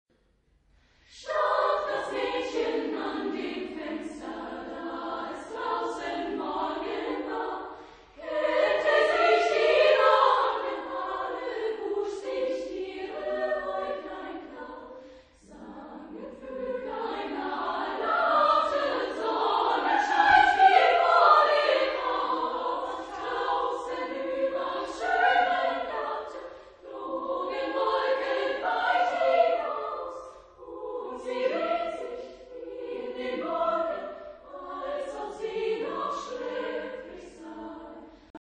Type of Choir: SSSA  (4 children OR women voices )
sung by Mädchenchor Montabaur
Discographic ref. : 7. Deutscher Chorwettbewerb 2006 Kiel